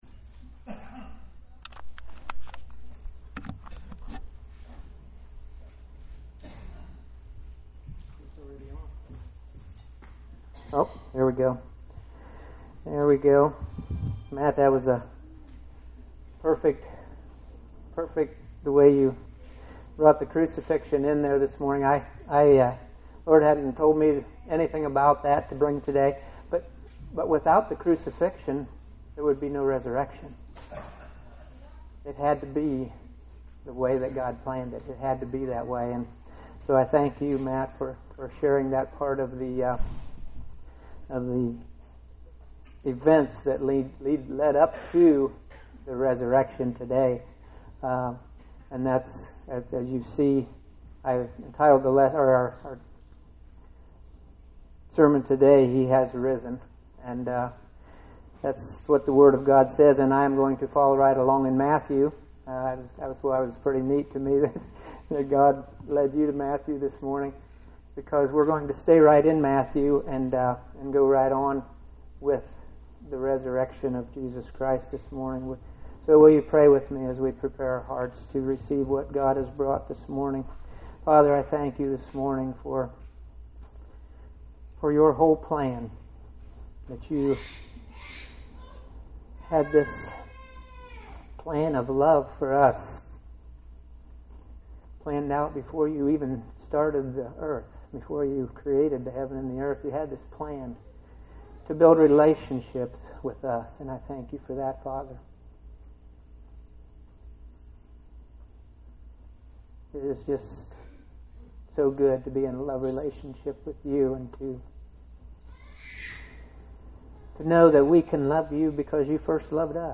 Words read from the song “He’s Alive”. Also music from the Revelation Song.